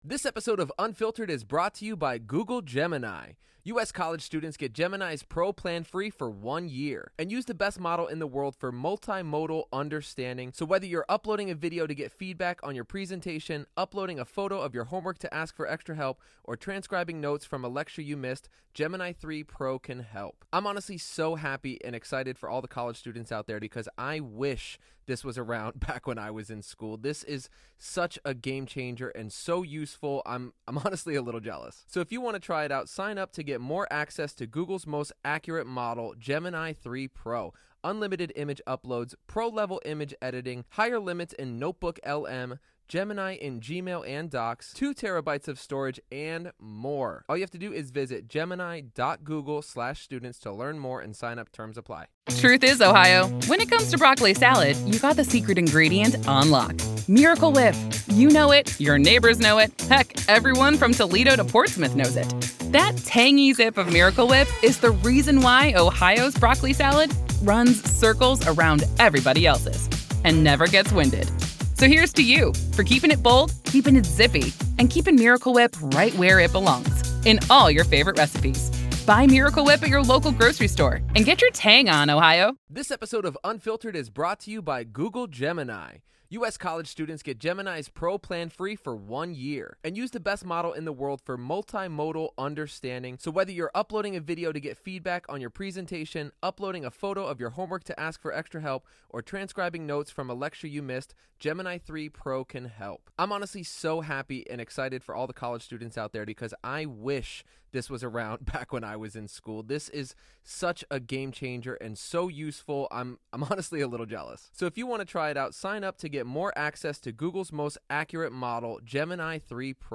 Each day’s proceedings bring new testimony, evidence, and revelations about what happened inside Richneck Elementary School on January 6, 2023 — and the administrative failures that followed. You’ll hear unfiltered courtroom audio, direct from the trial
Hidden Killers brings you the voices, the arguments, and the raw sound of justice in progress — as a jury decides whether silence and inaction inside a public school can rise to the level of legal accountability.